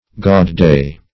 Search Result for " gaud-day" : The Collaborative International Dictionary of English v.0.48: Gaud-day \Gaud"-day`\, n. See Gaudy , a feast.